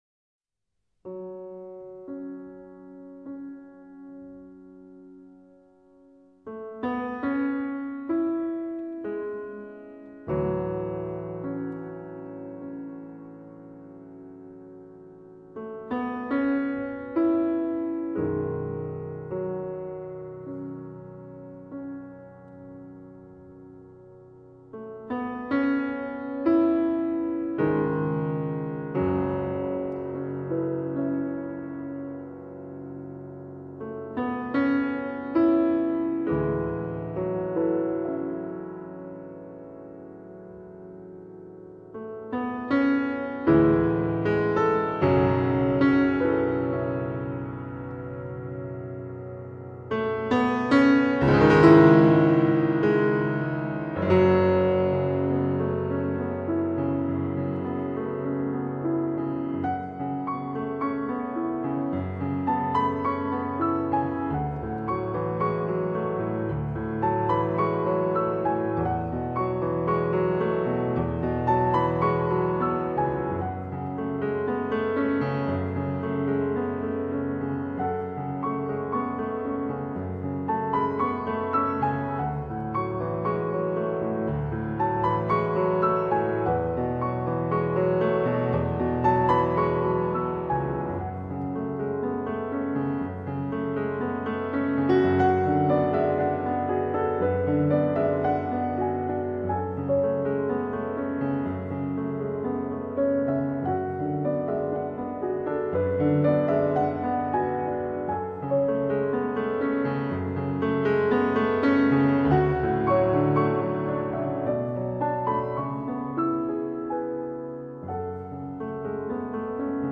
流派: 古典